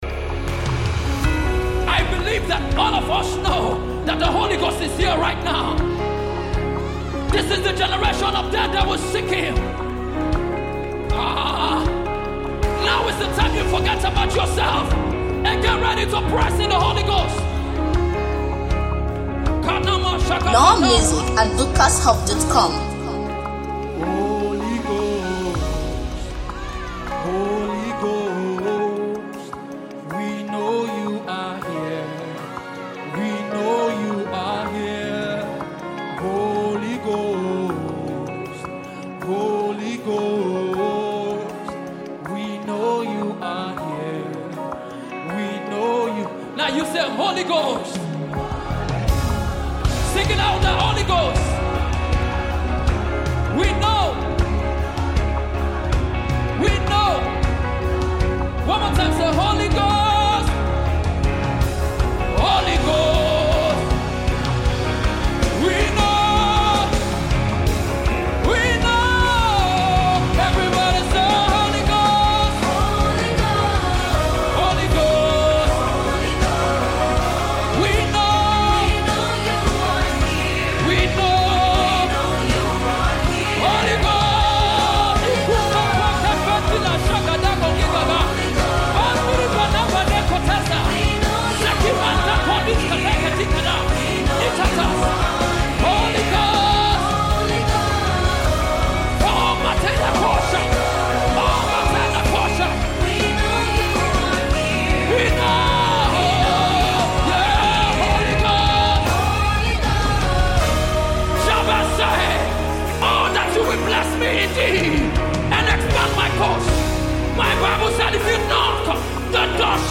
energetic soul-stirring melody